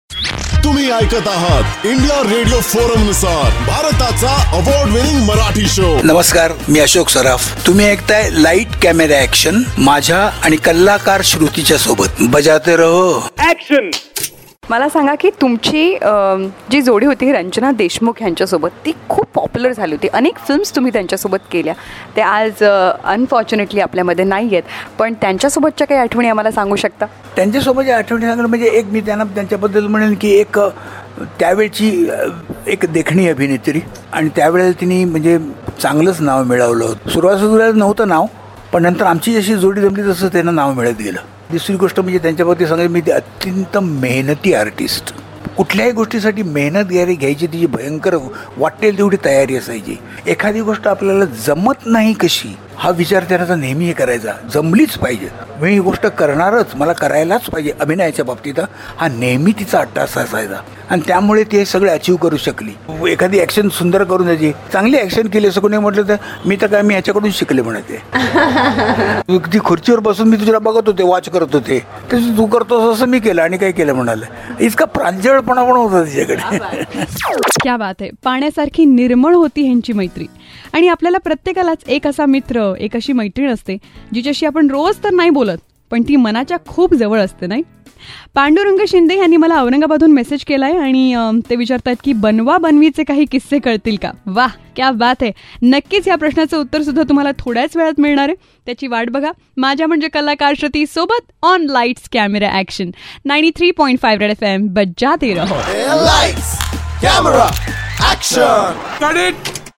VETRAN ACTOR ASHOK SARAF TALKS ABOUT HIS CO STAR RANJANA WHO HE WAS PAIRED WITH QUIET OFTEN